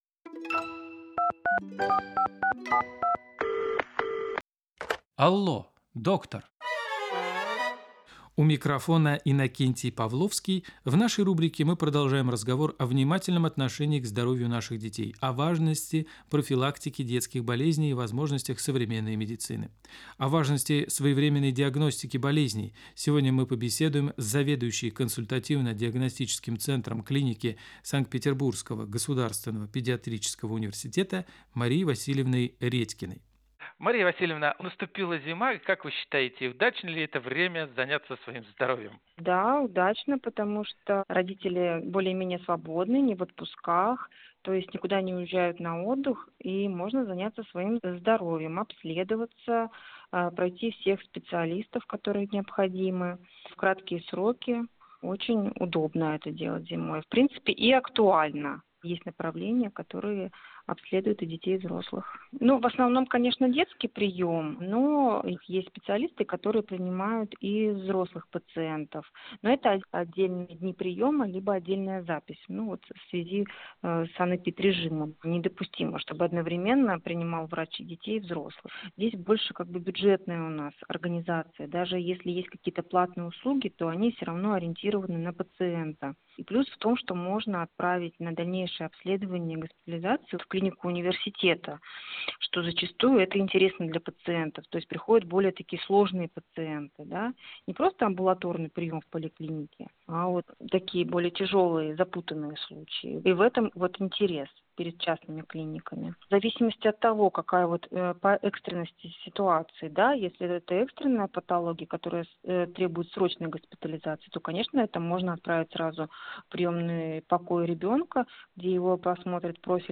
в эфире программы «Алло доктор» на радио «Петербург»